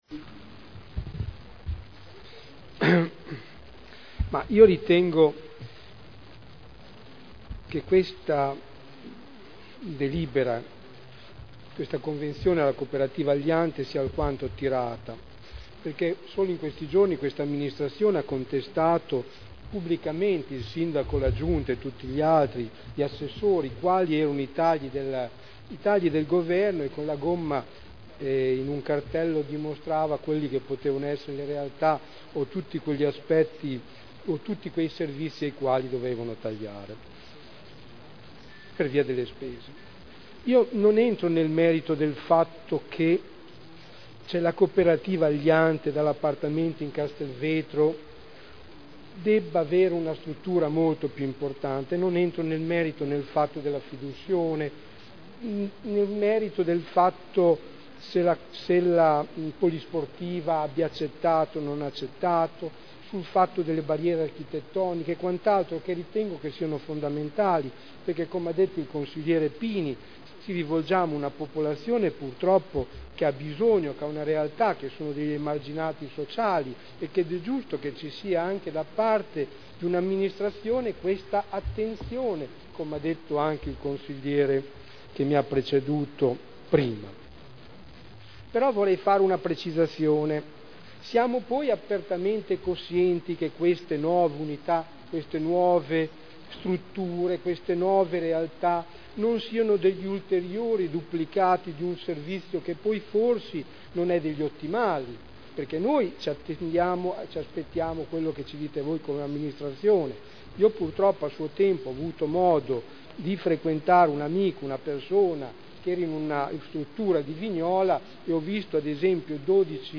Sergio Celloni — Sito Audio Consiglio Comunale
Seduta dell'8/11/2010. Convenzione per la costituzione del diritto di superficie a favore Aliante Coop. Sociale a r.l. per la realizzazione di un centro di accoglienza per la popolazione anziana con problemi psichiatrici in via Paltrinieri (Commissione consiliare del 28 ottobre 2010)